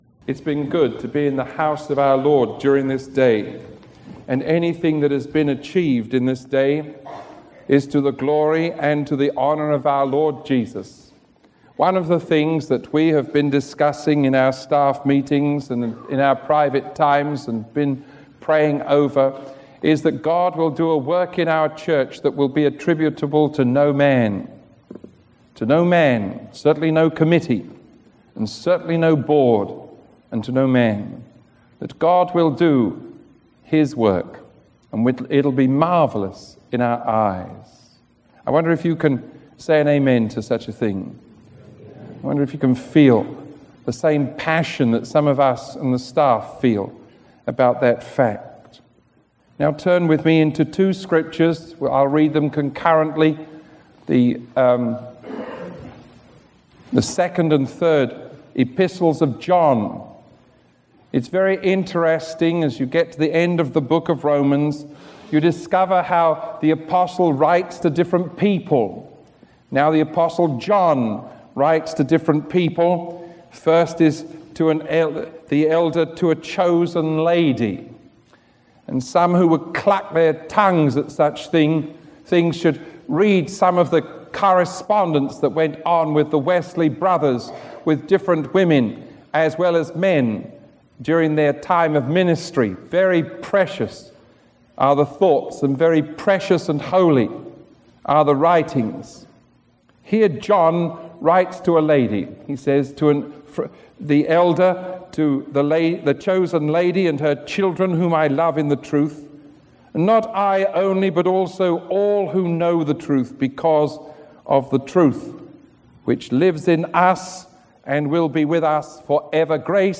Sermon 1051A recorded on January 5